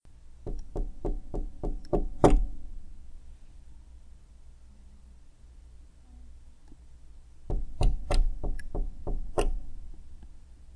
敲门声2
Tag: 敲的门 木制门